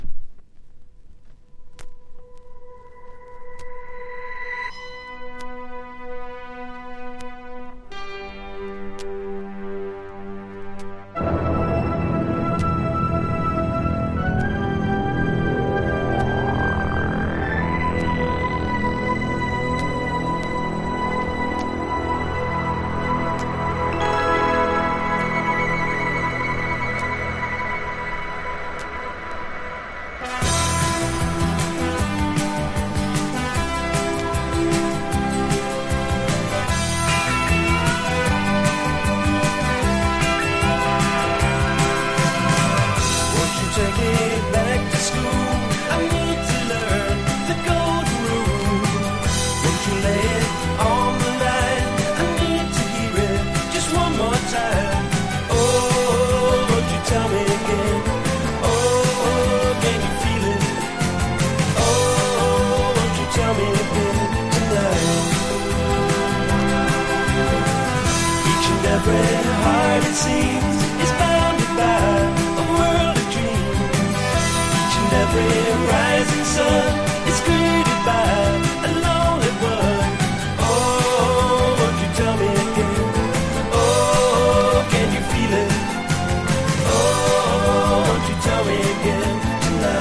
FOLK# 70’s ROCK# 80’s ROCK / POPS